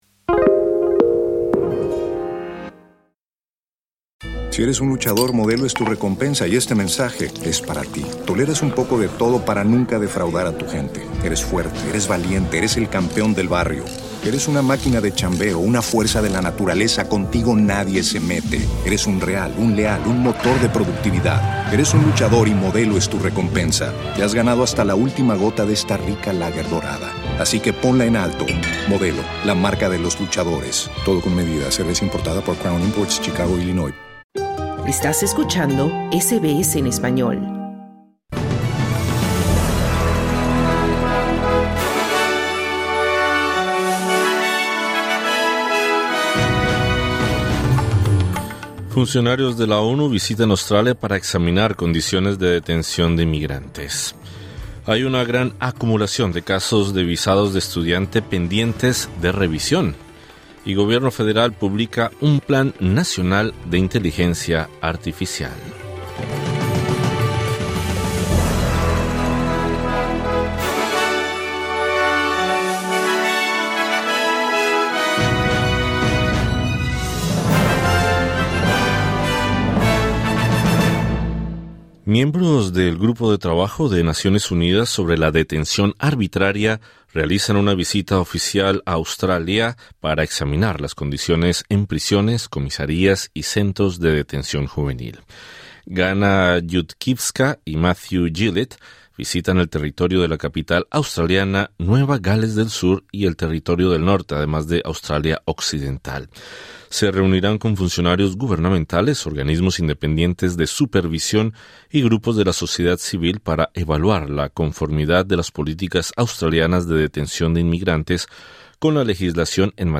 Miembros del Grupo de Trabajo de las Naciones Unidas sobre la Detención Arbitraria realizan una visita oficial a Australia para examinar las condiciones en prisiones y centros de detención. Escucha el resumen informativo de este martes 2 de diciembre 2025.